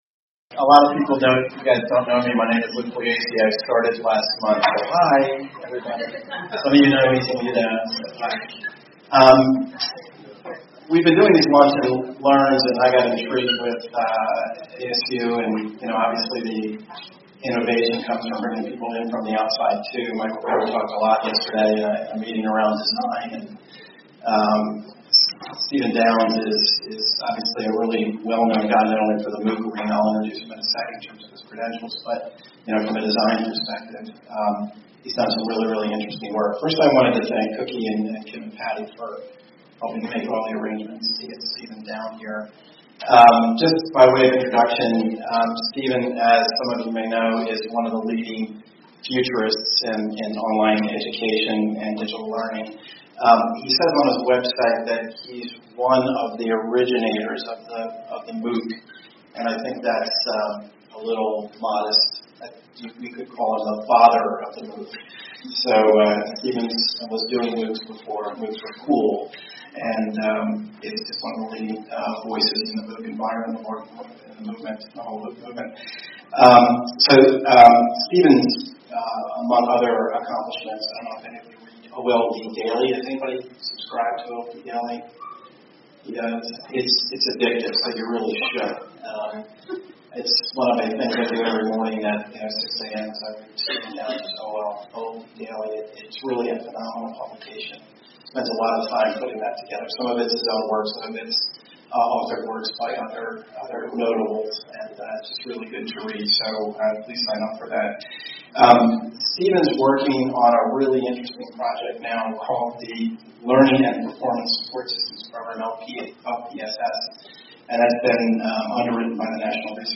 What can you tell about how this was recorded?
Open Education and & Personal Learning Previous Next Page: / Author: Downloads: (Old style) [ Slides ] [ PDF ] [ Audio ] [] Lunch and Learn Workshop, University of Arizona, Tempe, Arizona, Lecture, Aug 20, 2015.